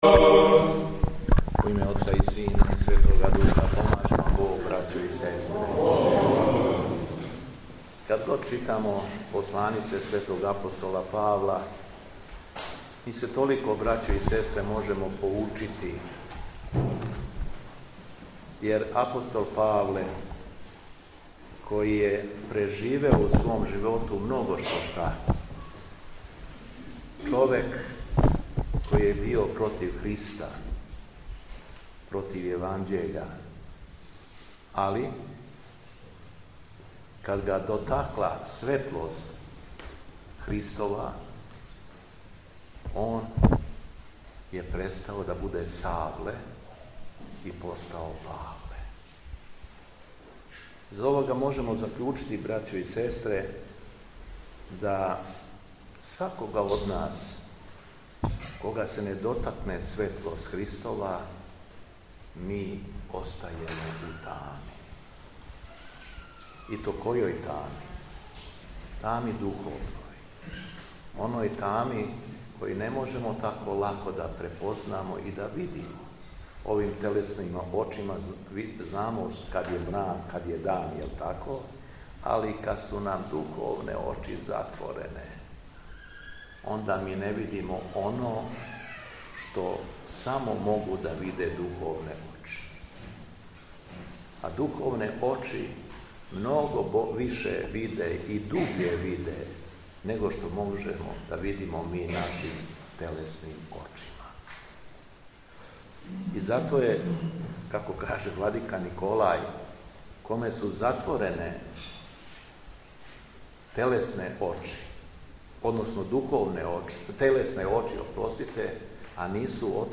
Беседа Његовог Високопреосвештенства Митрополита шумадијског г. Јована
У суботу 11. октобра 2025. године, Његово Високопресвештенство Митрополит шумадијски Г. Јован служио је Свету Архијерејску Литургију у храму Светог Николе у крагујевачком насељу Ердеч.